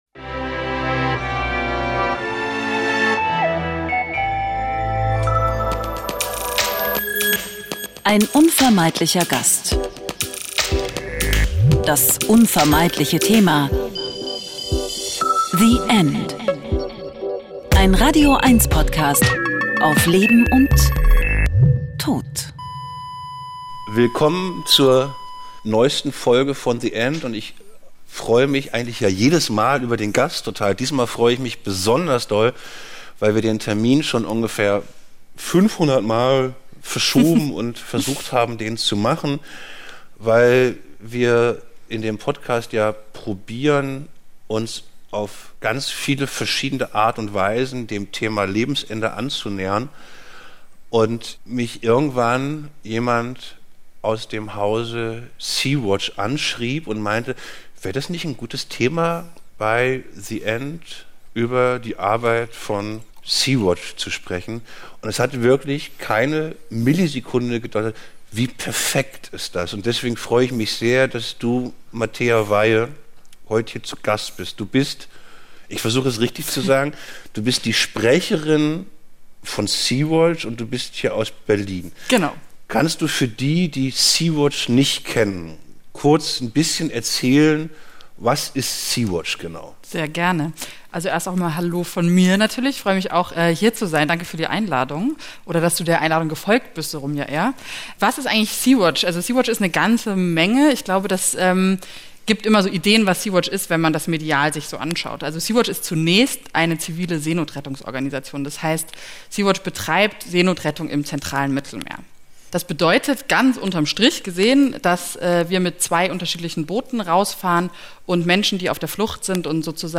Die Helfer*innen im Mittelmeer ertragen Seekrankheit und wochenlange Enge auf dem Boot, um den Menschen das Leben zu retten, die bereits alles verloren haben, Folter ausgesetzt waren und ohne diese Hilfe im Mittelmeer ertrinken würden. Ein Gespräch über Grenzerfahrungen und unsäglichen Schmerz, über Menschlichkeit und die Hoffnung auf Veränderung der Politik im Umgang mit Geflüchteten. Die Folge wurde in der Urania/Berlin vor Publikum aufgezeichnet.